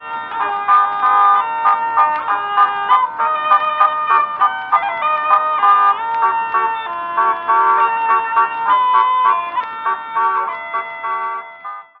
The Irish Traditional Music Tune Index
WaltzG Major
pipes